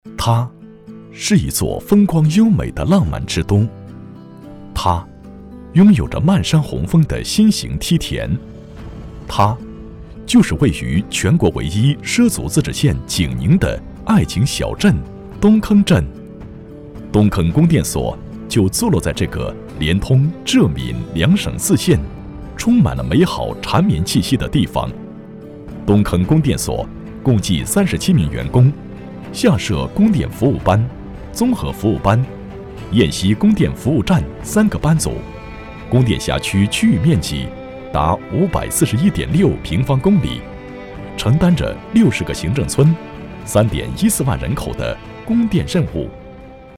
专题男254号（供电所）
成熟稳重 企业专题,人物专题,医疗专题,学校专题,产品解说,警示教育,规划总结配音
大气稳重男中音，厚重磁性。